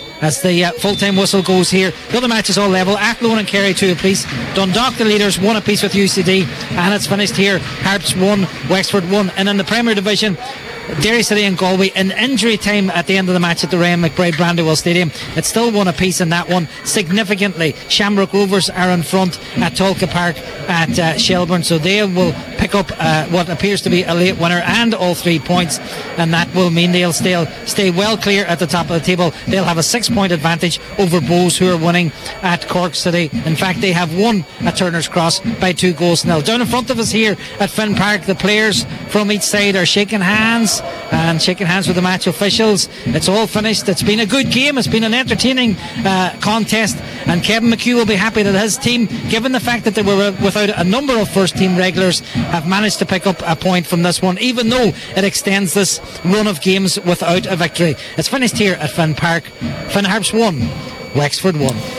live at full time in Ballybofey